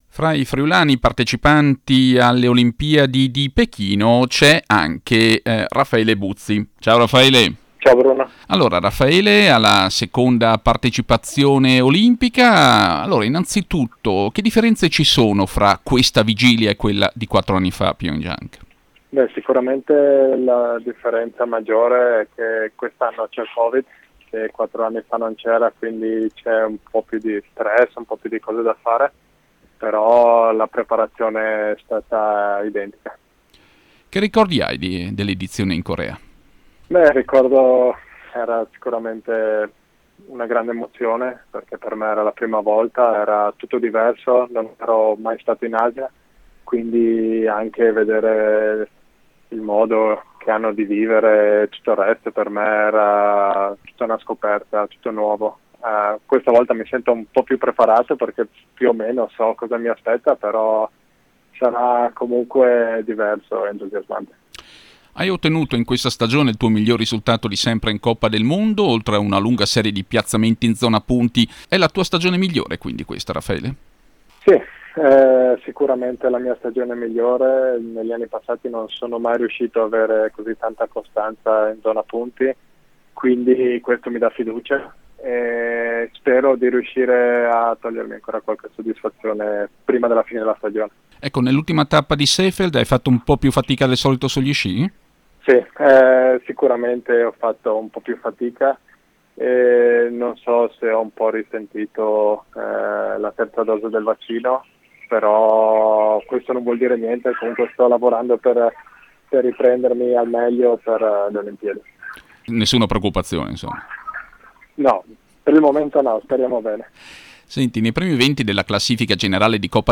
Audio intervista al tarvisiano, in gara alle Olimpiadi 2022 nella combinata nordica